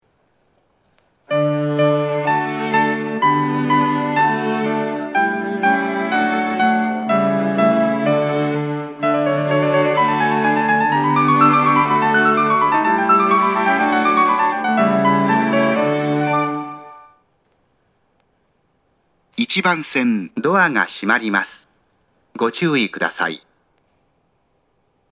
発車メロディー
フルコーラスです。